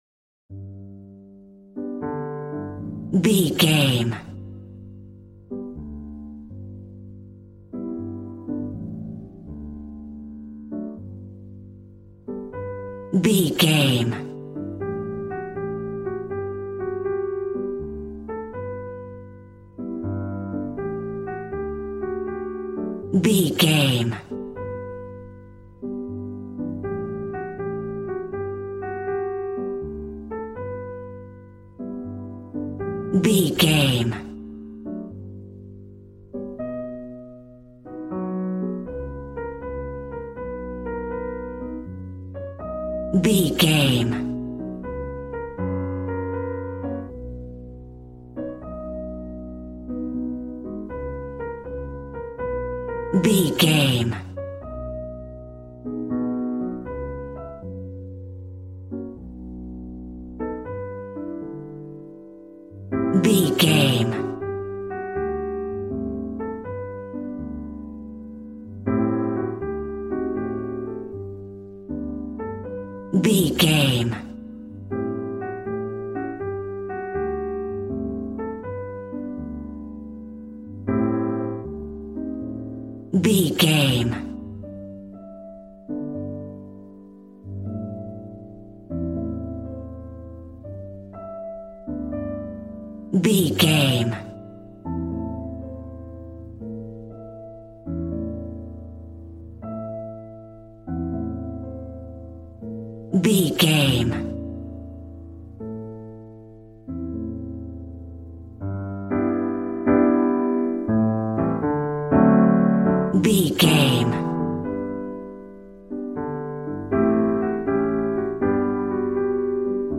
Smooth jazz piano mixed with jazz bass and cool jazz drums.,
Ionian/Major